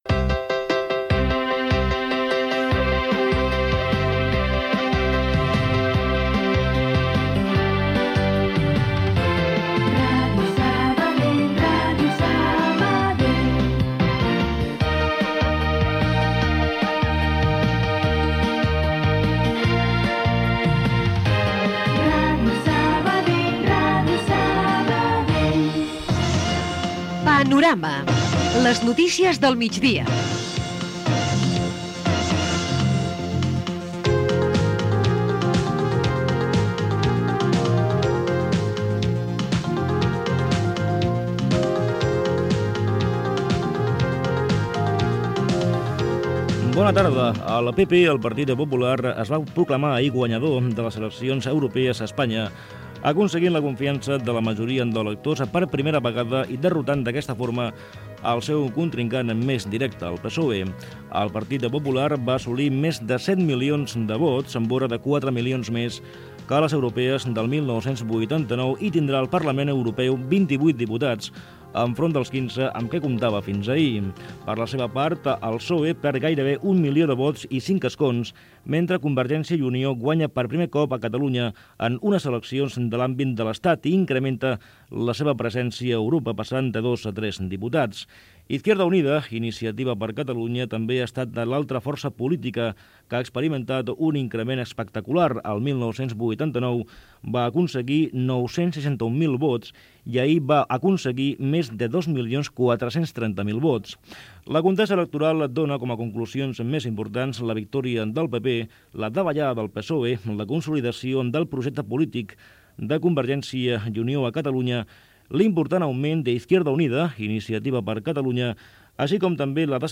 Indicatiude l'emissora, careta del programa, informació sobre les eleccions europees guanyades a Espanya pel Partido Popular, titulars, tertúlia amb representats dels partits polítics
Informatiu